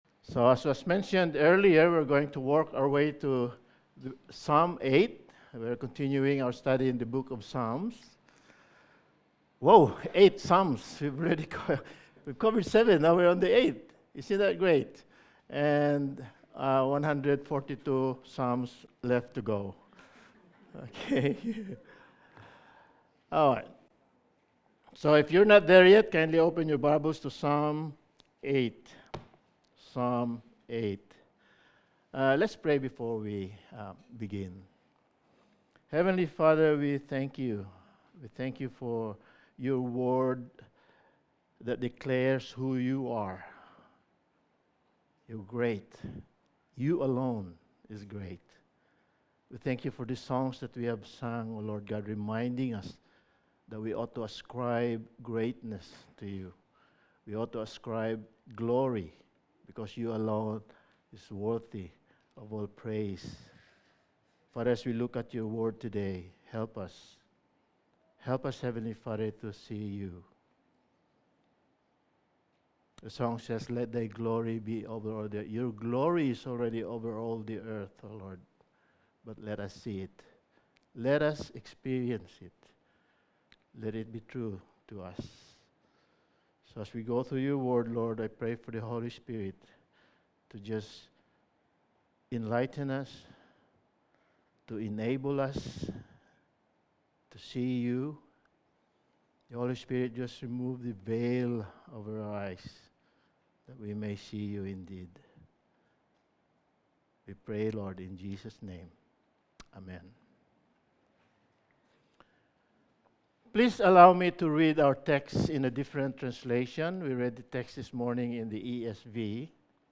Sermon
Passage: Psalm 8:1-9 Service Type: Sunday Morning Sermon 9 « Toledot 2